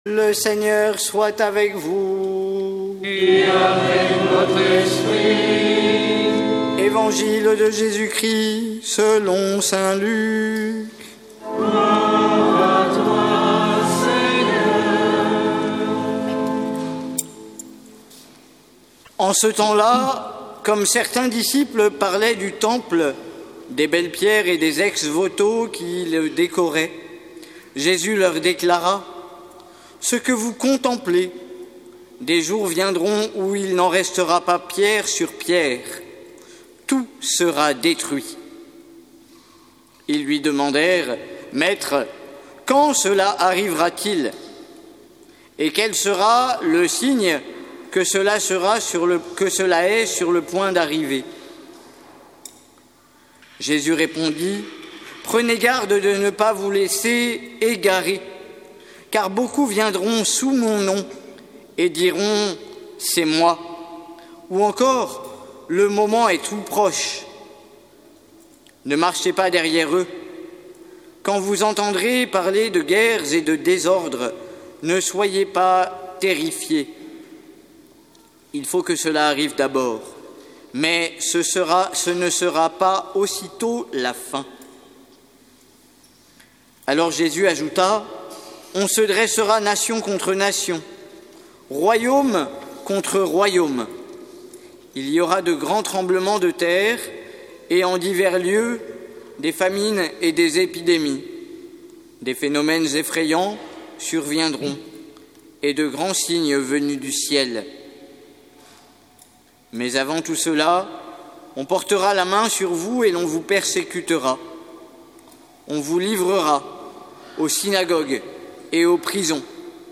Évangile de Jésus Christ selon saint Luc avec l'homélie